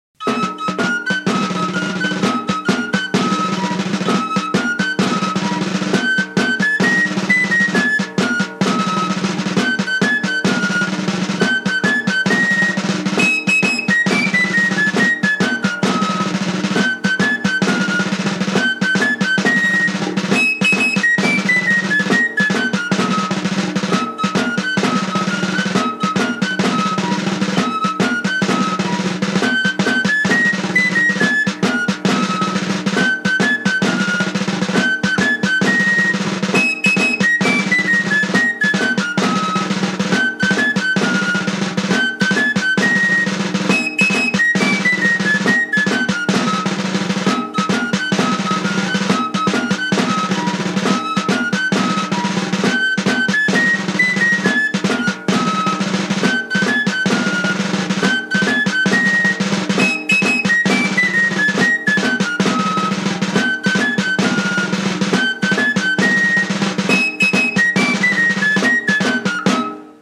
Larraungo Herri dantzak Almute dantza de Baztan. Txistua.